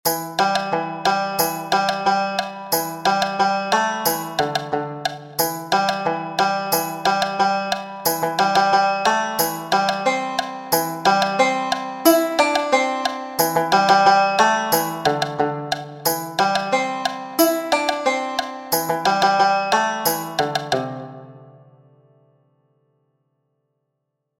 Also notice the banjo in the score and another interesting thing: pentatonic scale.
Tideo tune